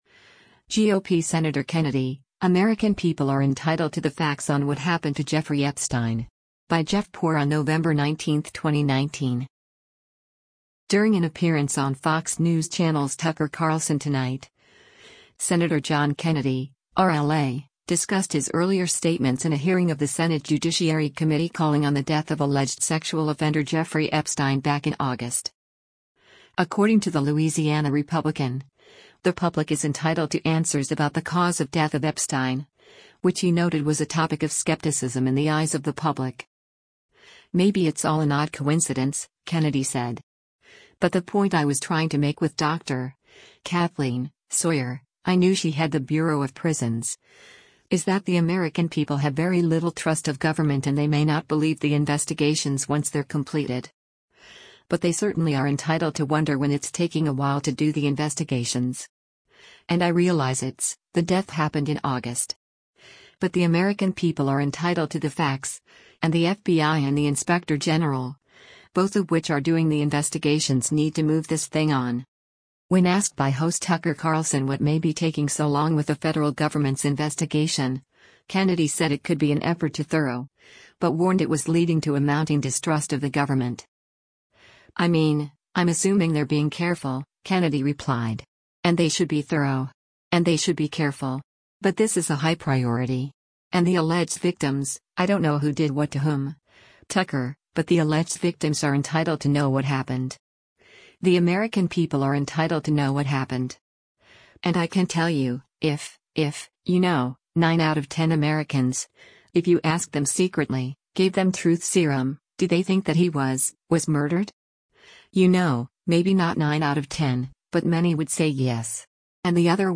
During an appearance on Fox News Channel’s “Tucker Carlson Tonight,” Sen. John Kennedy (R-LA) discussed his earlier statements in a hearing of the Senate Judiciary Committee calling on the death of alleged sexual offender Jeffrey Epstein back in August.
When asked by host Tucker Carlson what may be taking so long with the federal government’s investigation, Kennedy said it could be an effort to “thorough,” but warned it was leading to a mounting distrust of the government.